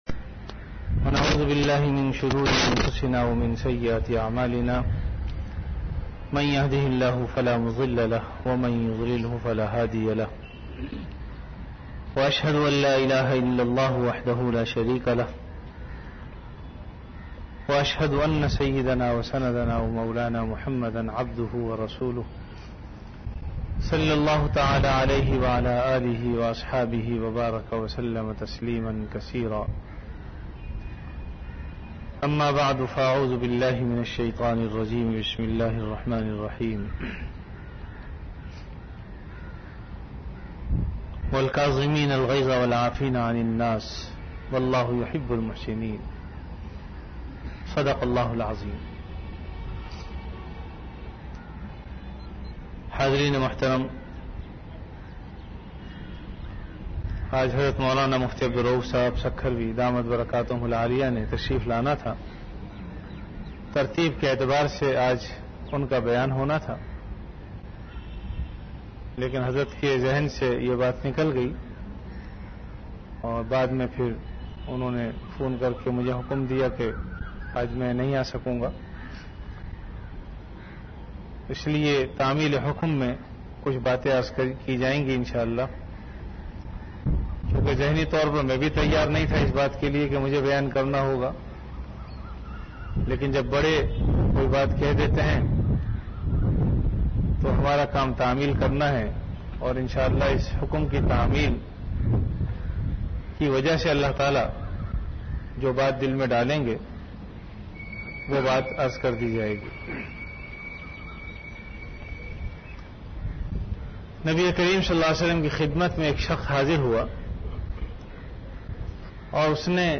Bayanat · Jamia Masjid Bait-ul-Mukkaram, Karachi
Event / Time After Asar Prayer